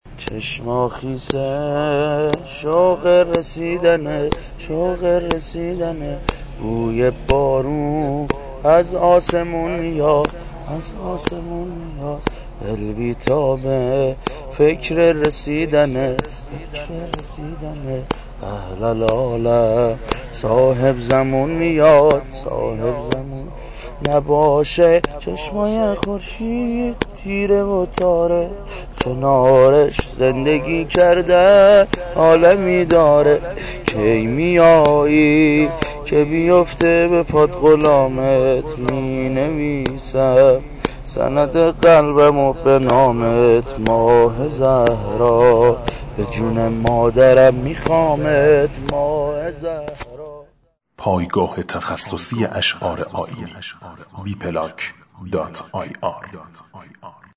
ولادت
سرود